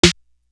Essence Snare.wav